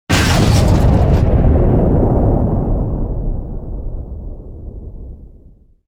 youhit3.wav